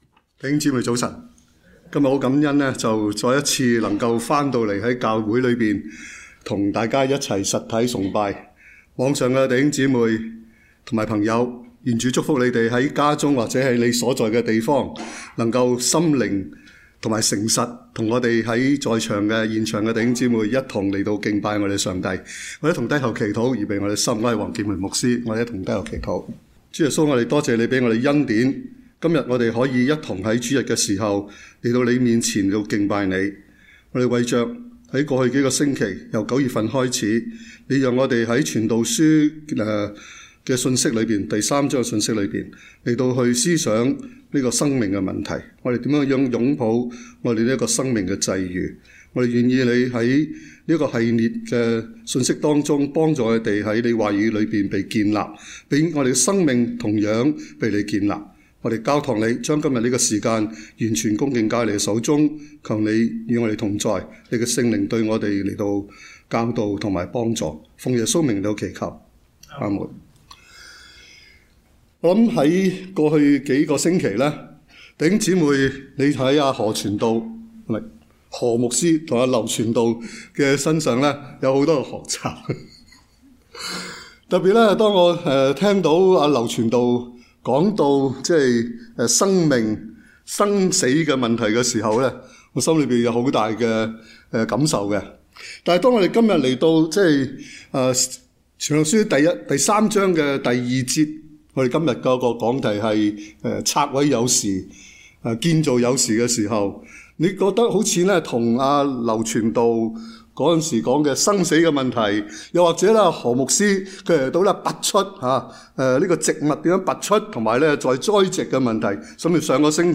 講道錄音：